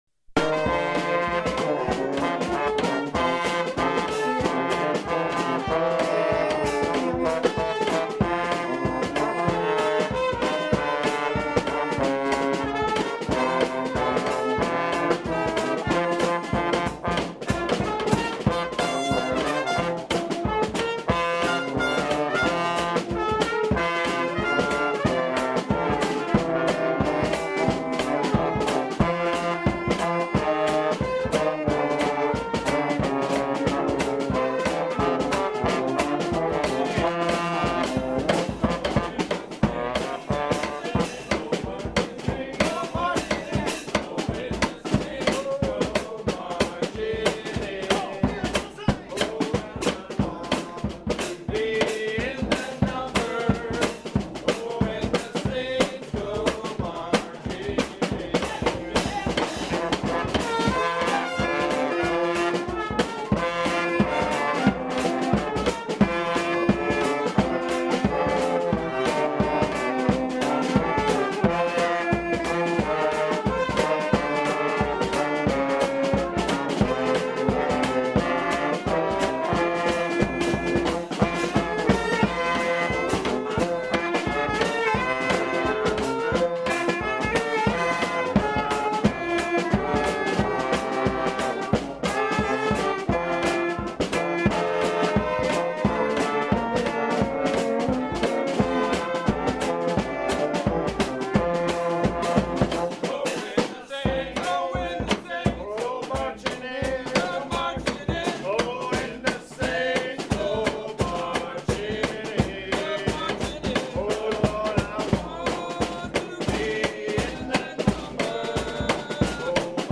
District Circus Marching Band (New Orleans 2nd line style)
dcpeace2ndrehersalSaints.mp3